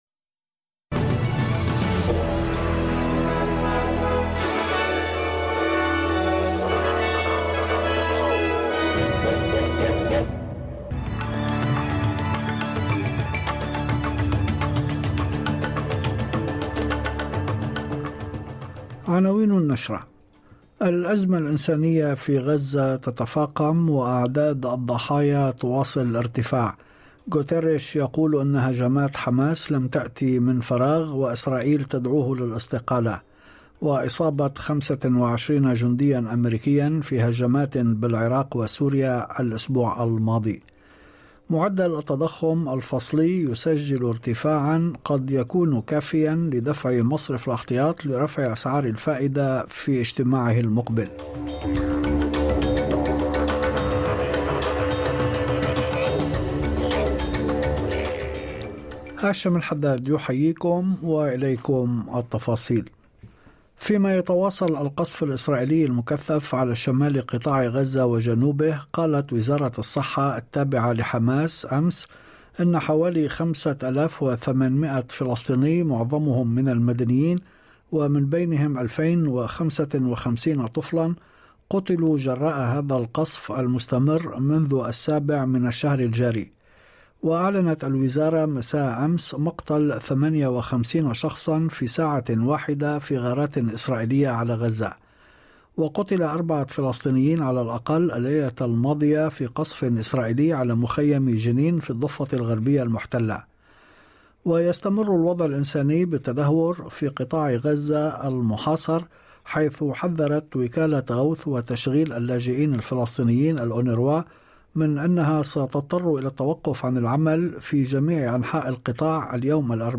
نشرة أخبار المساء 25/10/2023